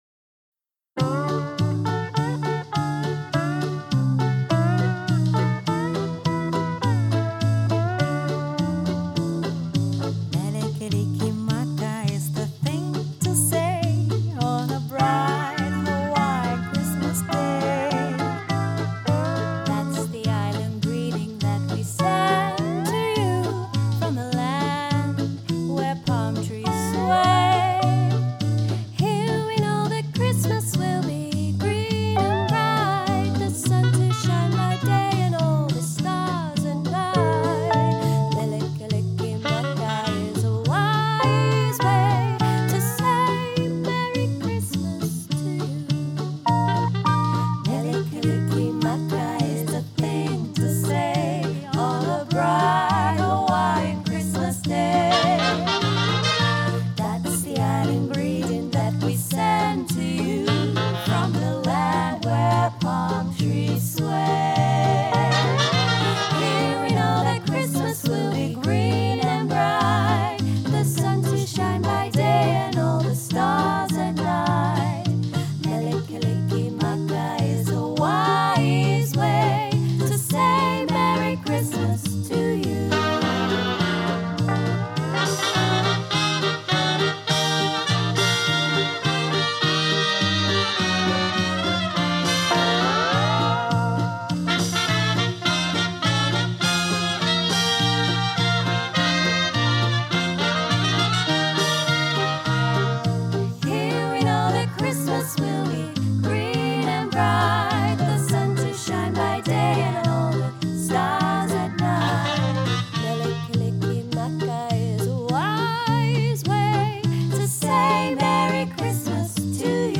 Three Female Vocal Harmony Speakeasy Swing Band for Hire
3 x Vocalists, Backing Tracks